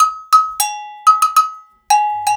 100 AGOGO 04.wav